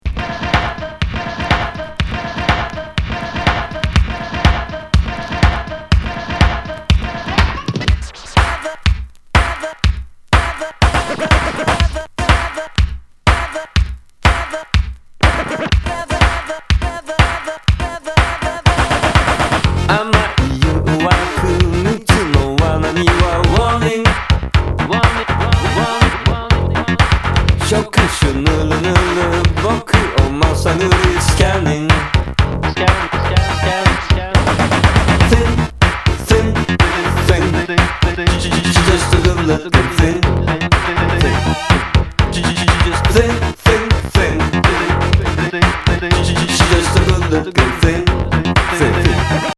エレクトロ・ビート傑作!キテレツ・エレクトロ・フリースタイル